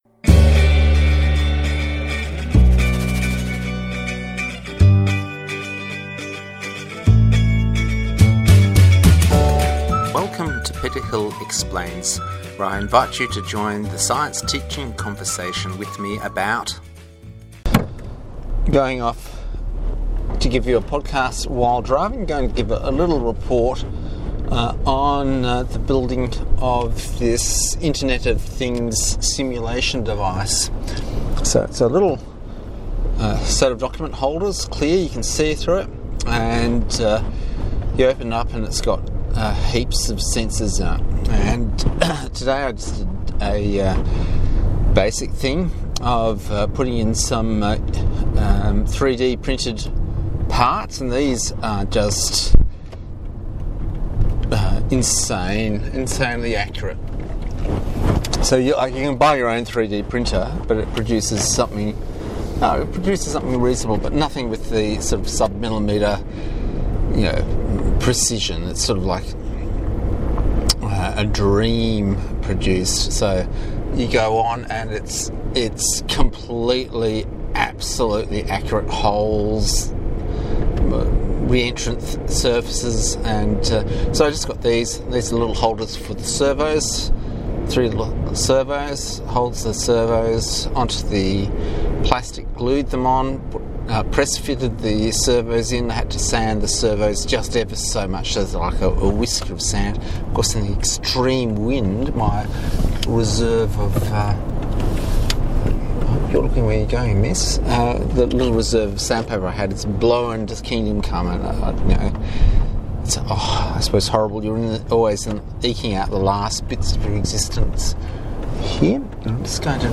A twenty minute drive arround a wind swept village as I report on the ultra slow development of and IoT module. Get a feel for yesteryear which is a day in the life of Australia.